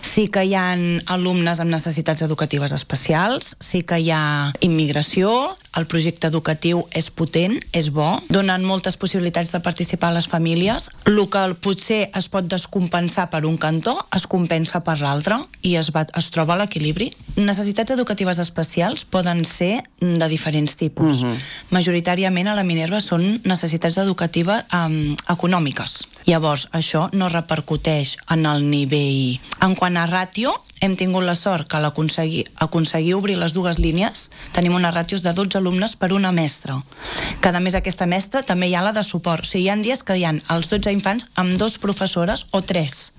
han passat aquest dilluns pel matinal de RCT per compartir la seva experiència després de dos mesos de curs.